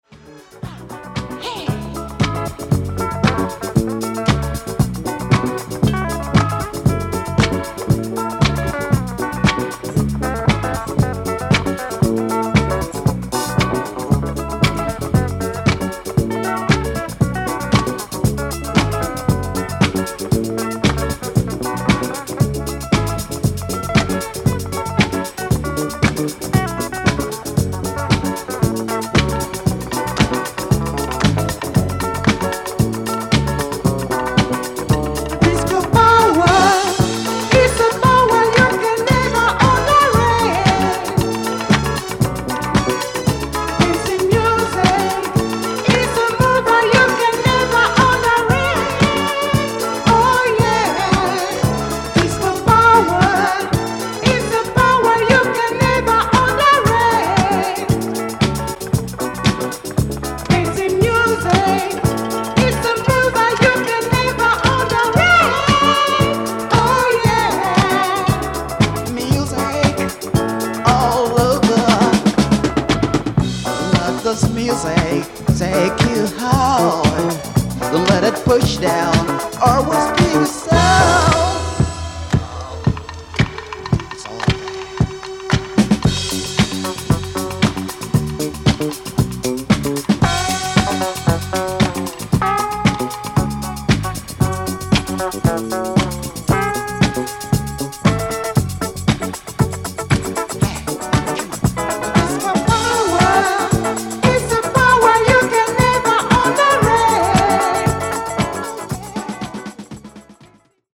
Nigerian disco supremo
Disco Funk